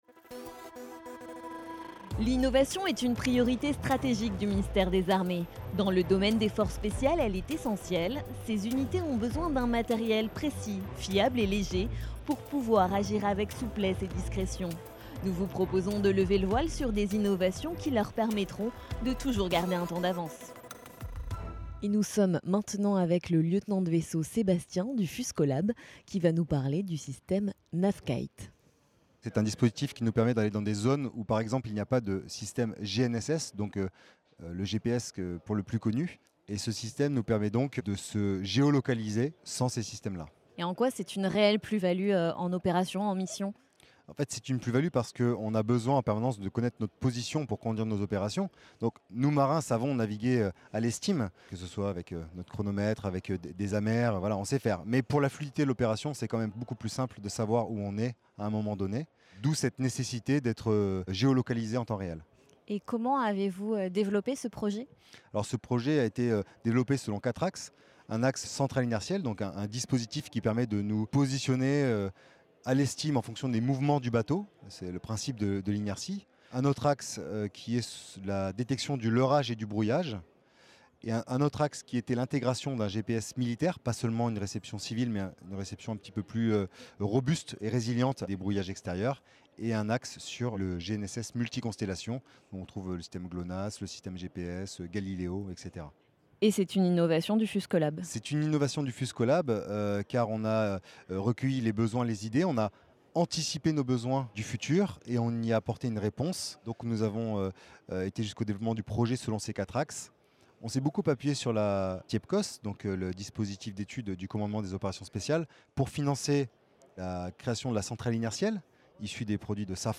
A l’occasion de la Journée mondiale de la créativité et de l’innovation, la rédaction vous propose, pendant deux jours, d’écouter des innovateurs civils et militaires qui ont mobilisé leurs idées et talents au service des forces spéciales.